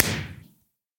fireworks
blast1.ogg